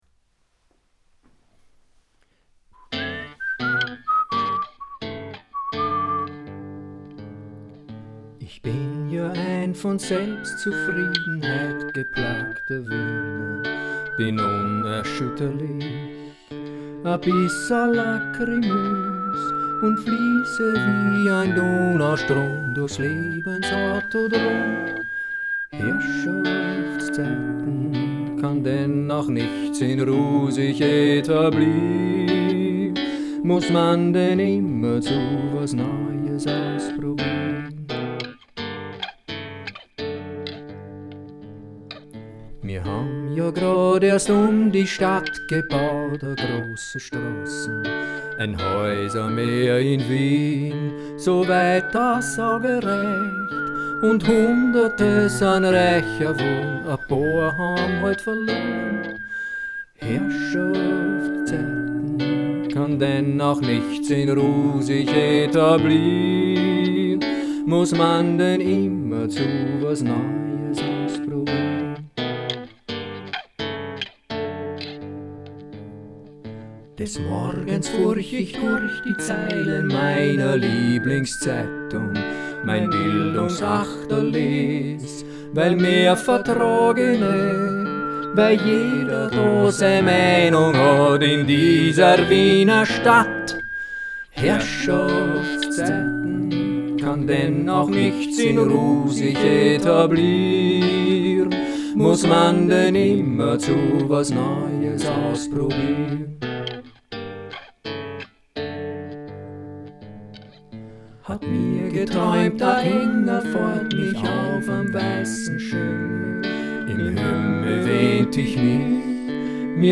— Ursprünglich für Klavier geschrieben habe ich letztendlich eine Version für Gitarre komponiert.
Composition, Aufnahme, Photo